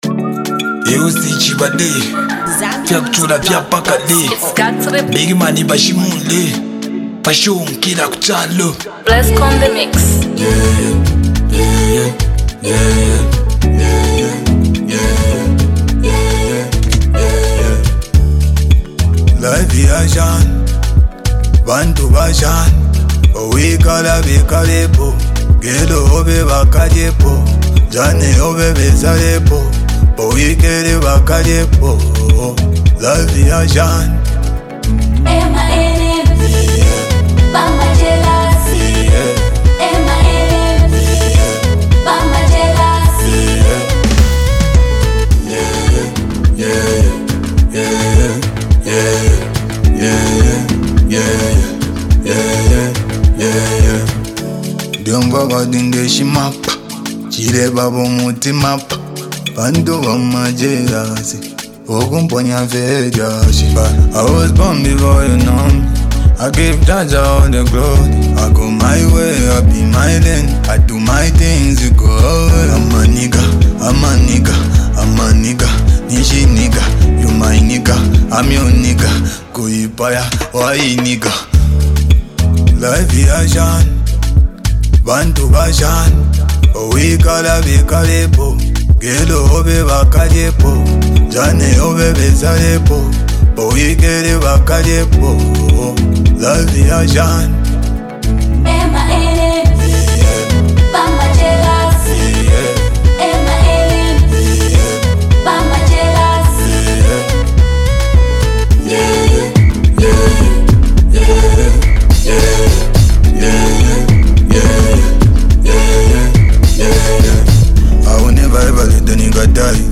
With a gritty beat, sharp lyrics, and confident delivery